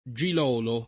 Gilolo [ J il 0 lo ]